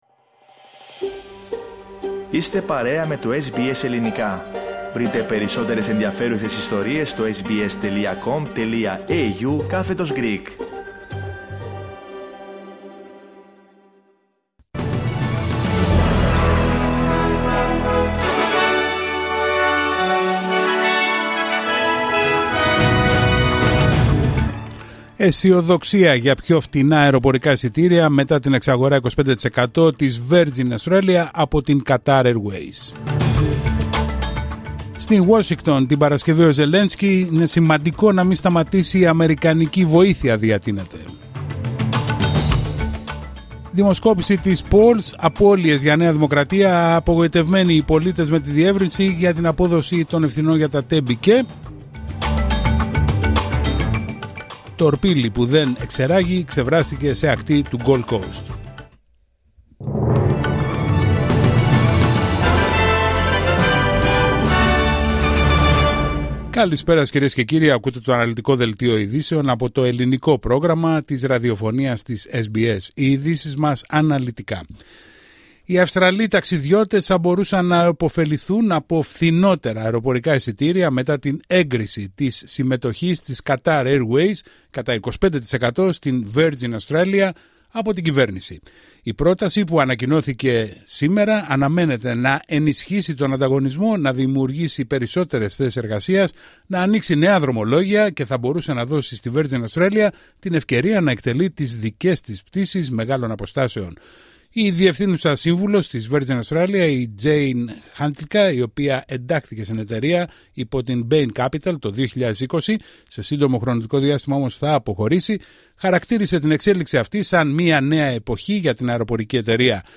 Δελτίο ειδήσεων Πέμπτη 27Φεβρουαρίου 2025